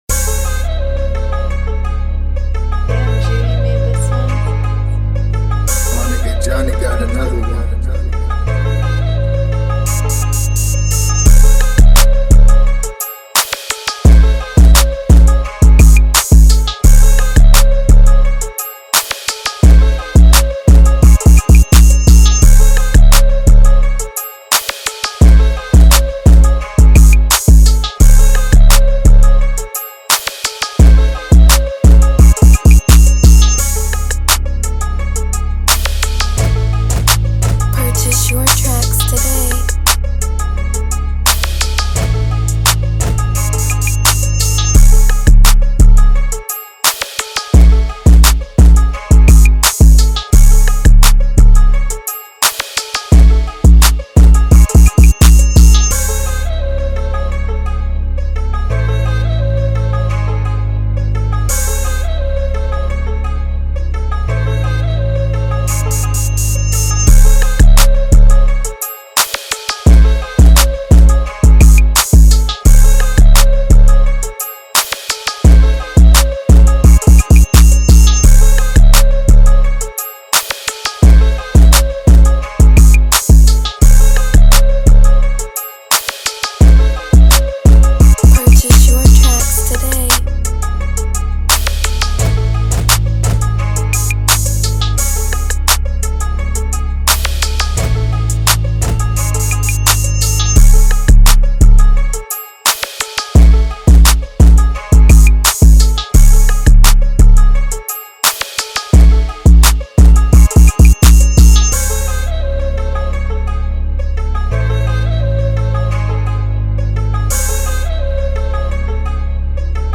This is the official instrumental